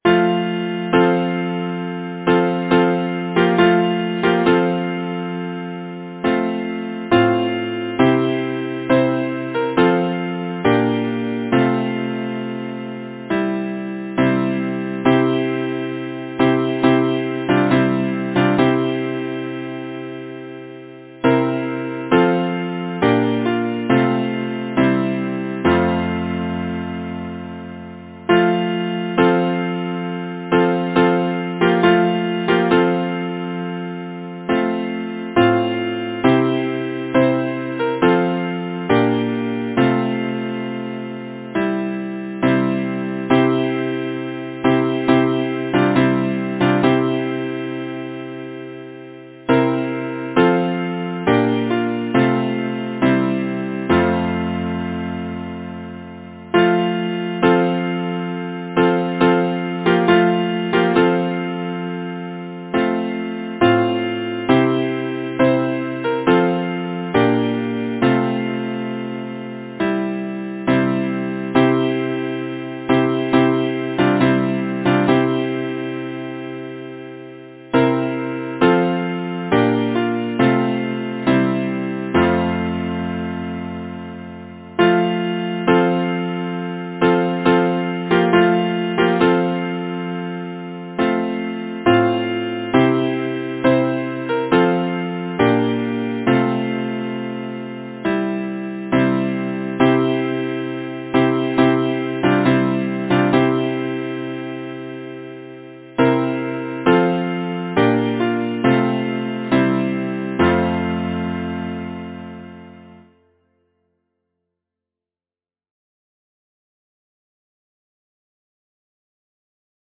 Title: At twilight hour Composers: Edward Roberts and John Paul Morgan Lyricist: Number of voices: 4vv Voicing: SATB Genre: Secular, Partsong
Language: English Instruments: A cappella